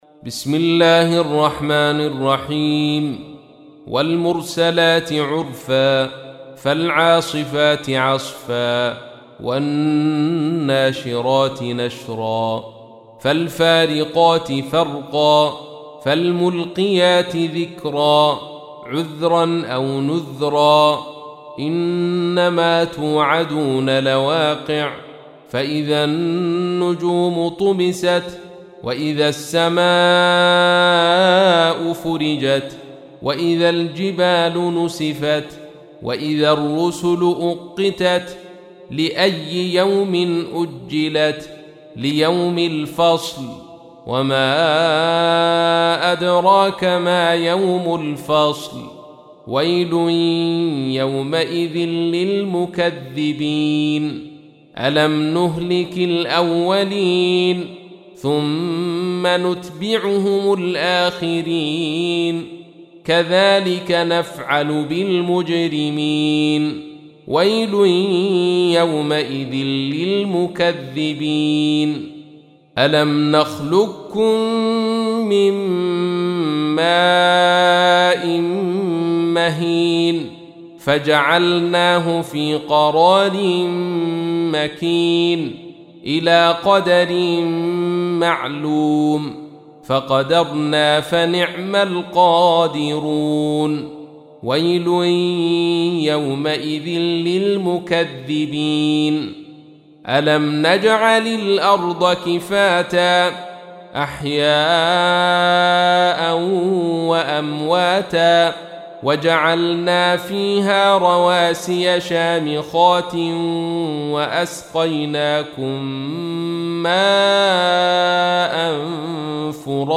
تحميل : 77. سورة المرسلات / القارئ عبد الرشيد صوفي / القرآن الكريم / موقع يا حسين